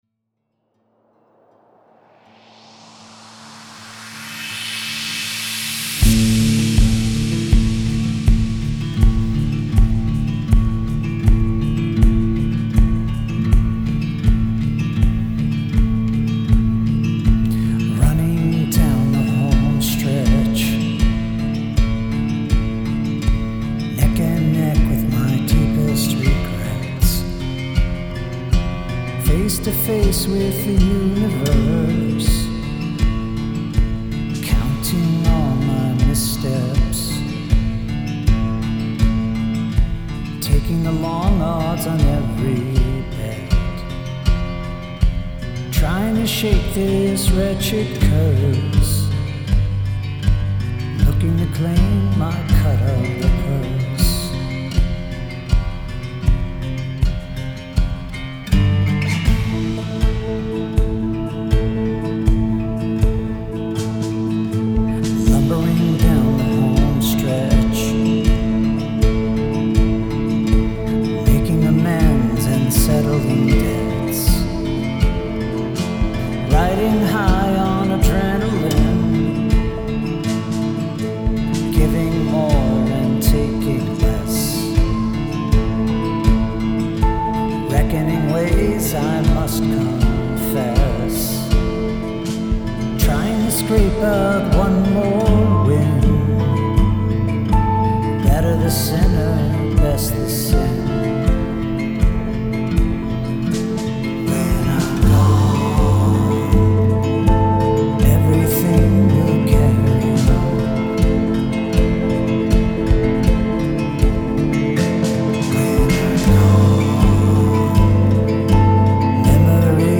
Polyrhythm